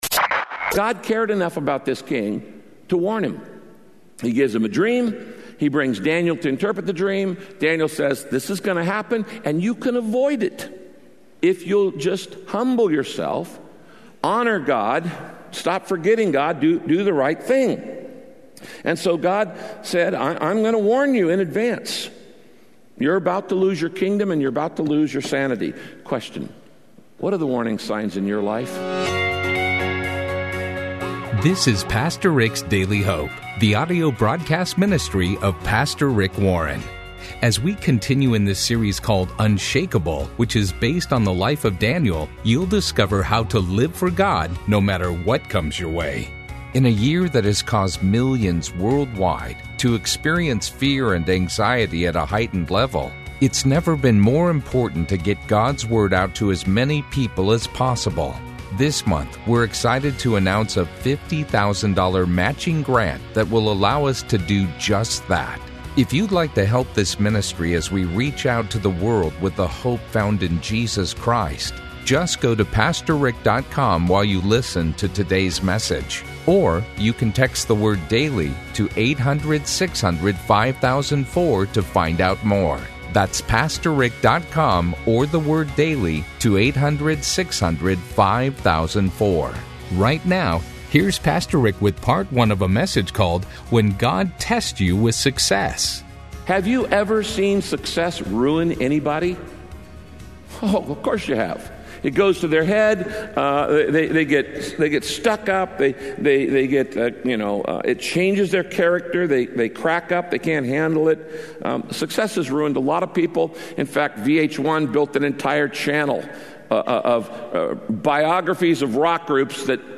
Radio Broadcast When God Tests You With Success – Part 1 Daniel teaches us that the first way God will test our success is if we will continue to trust in him.